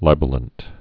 (lībə-lənt)